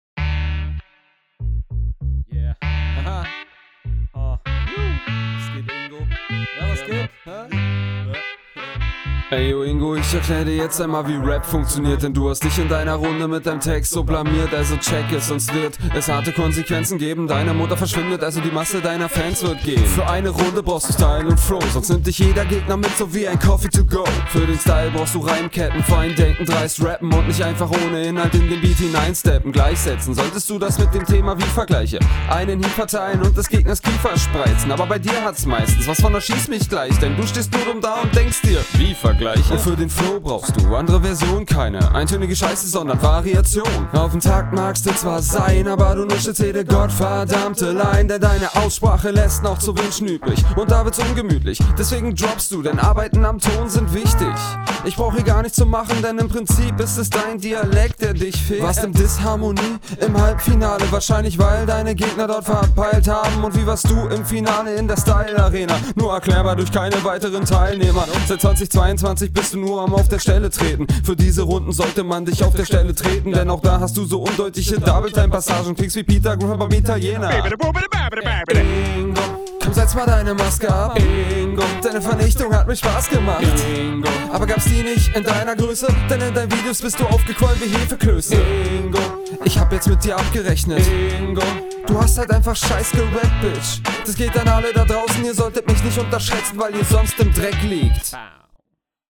Der Flow ist sehr stark und lädt zum Kopfnicken ein.